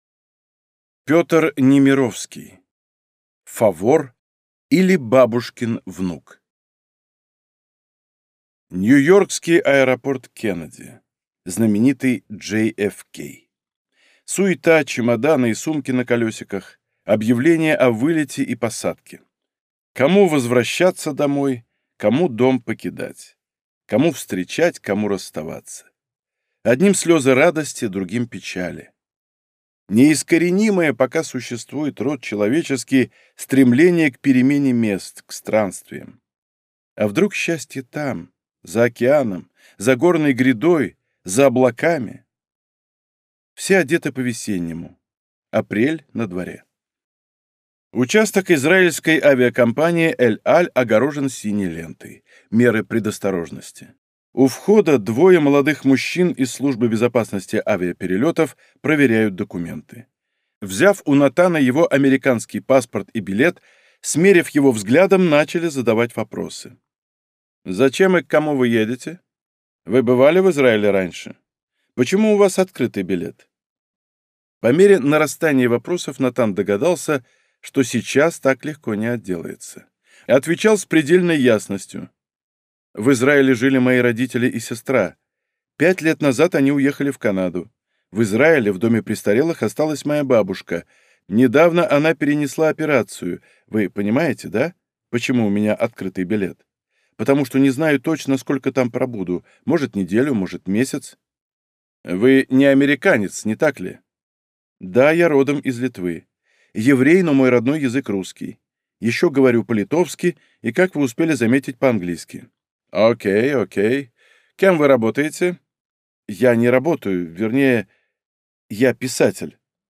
Аудиокнига Фавор, или Бабушкин внук | Библиотека аудиокниг